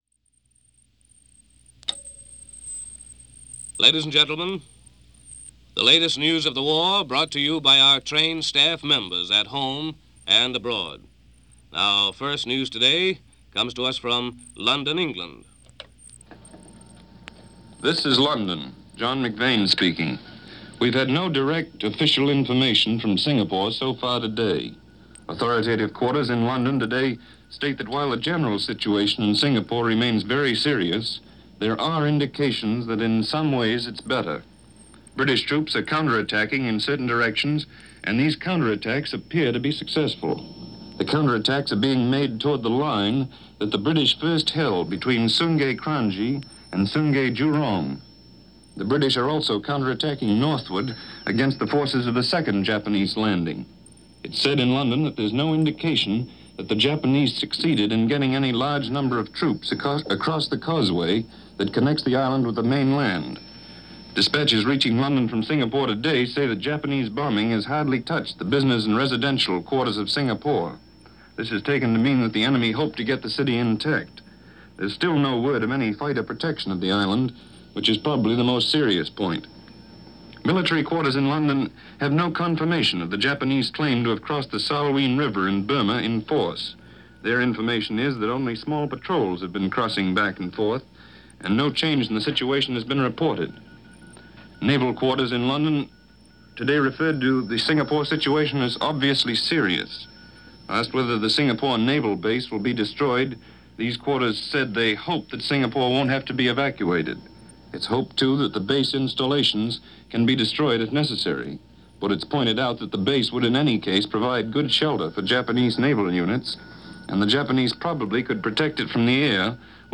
News of this day in 1942